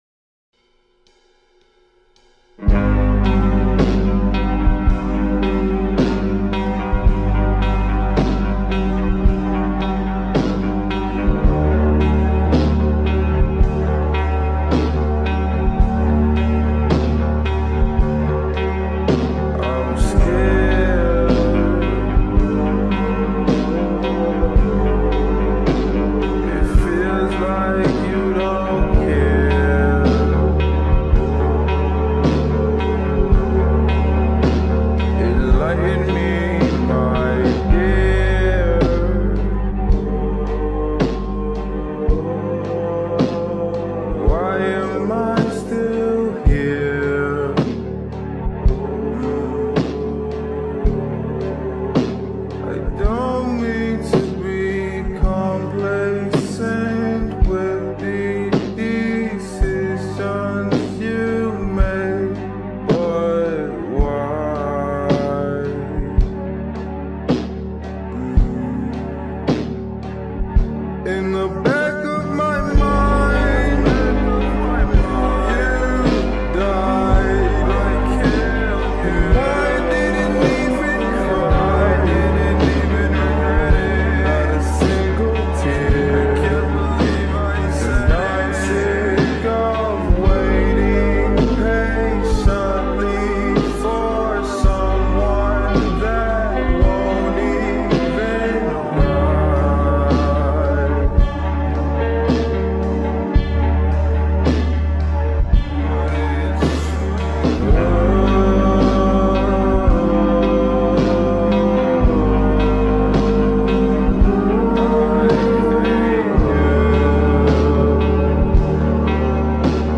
این اهنگ در ورژن کند شده است و یک اهنگ احساسی و غمگین است
غمگین